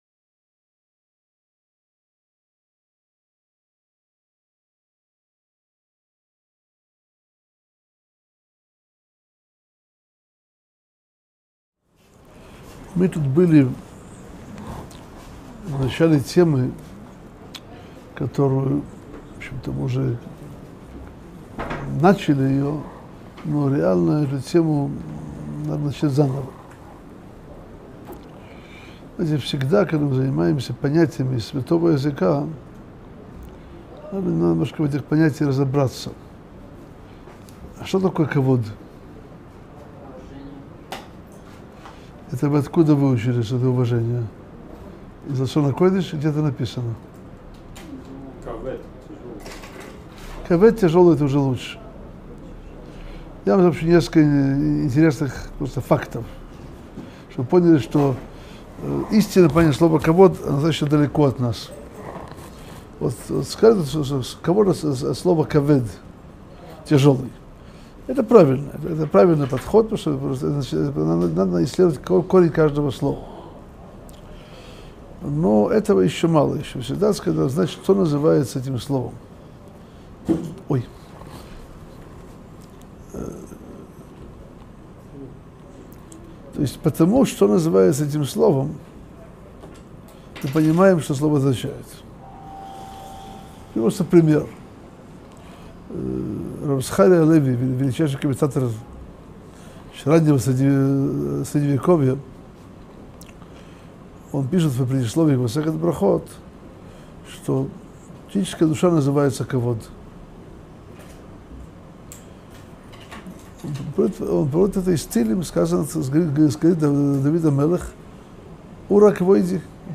Пути приобретения Торы - Урок 77 - Высший источник понятия Кавод - Сайт о Торе, иудаизме и евреях